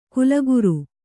♪ kulaguru